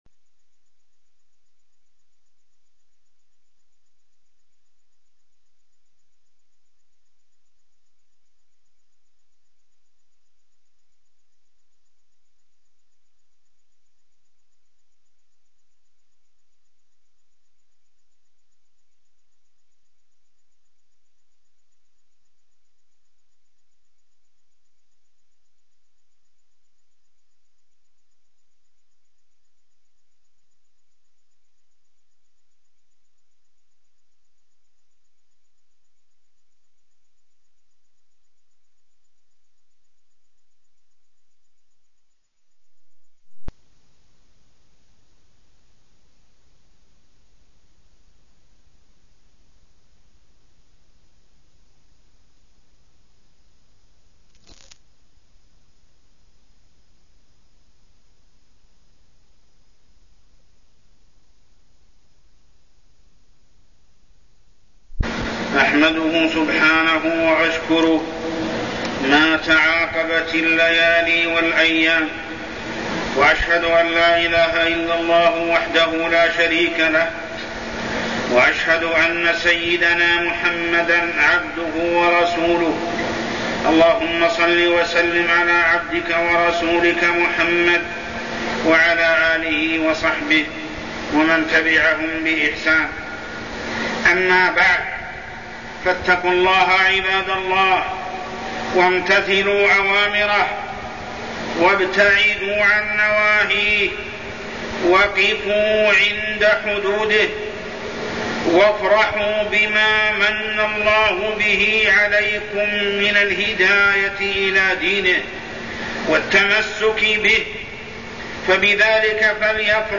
تاريخ النشر ٢٩ ربيع الأول ١٤٠٨ هـ المكان: المسجد الحرام الشيخ: محمد بن عبد الله السبيل محمد بن عبد الله السبيل الإستقامة